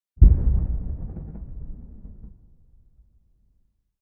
Distant explosion
artillery blast bomb detonation distant explode explosion far sound effect free sound royalty free Memes